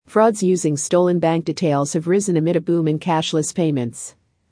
【ノーマル・スピード】
答案の分析が済んだら、ネイティブ音声を完全にコピーするつもりで音読を反復してくださいね。